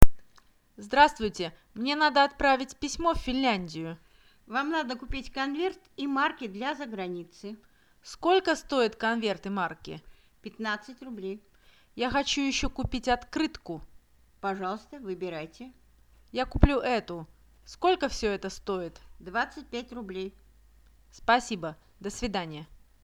Kuuntele ensin keskustelu ja vastaa sitten kysymyksiin.